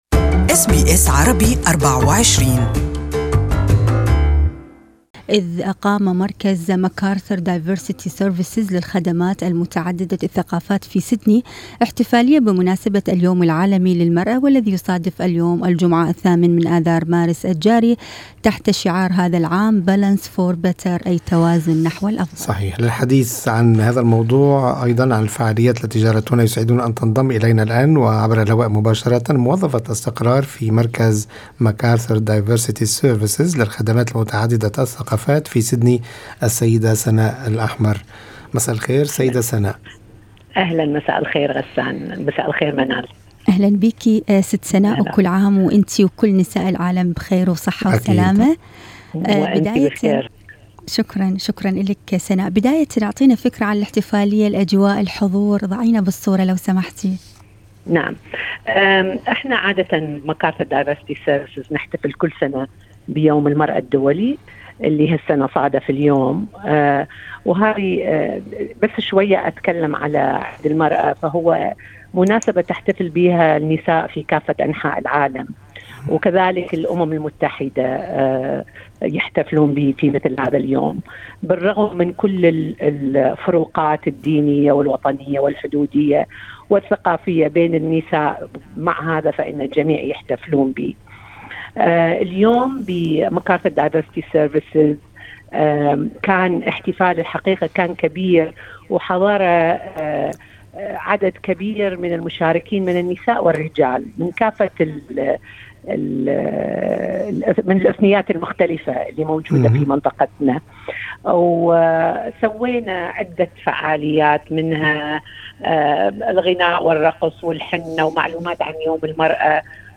This interview is only in Arabic